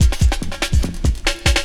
16 LOOP02 -R.wav